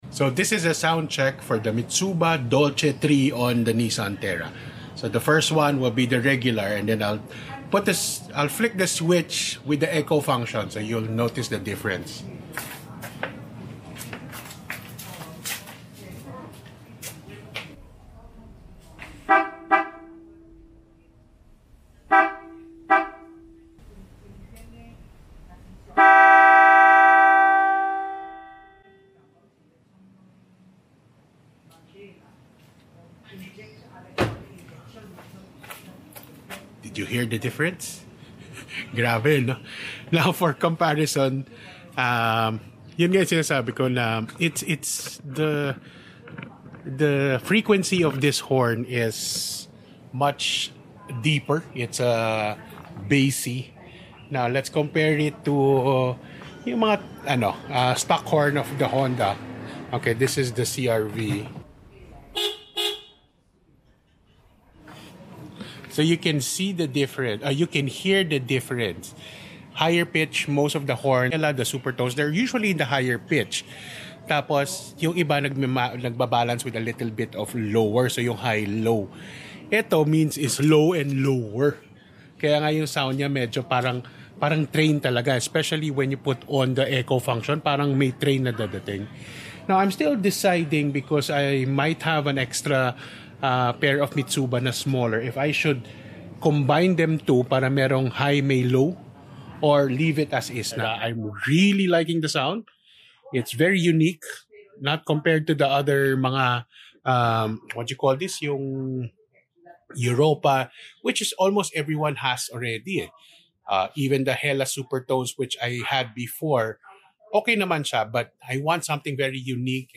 Mitsuba Dolce III Sound Check Sound Effects Free Download